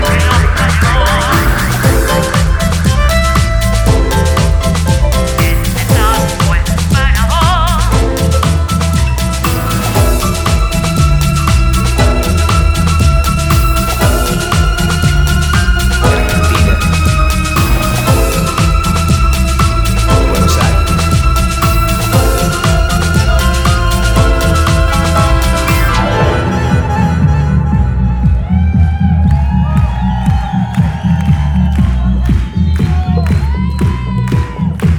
Жанр: Рок / Альтернатива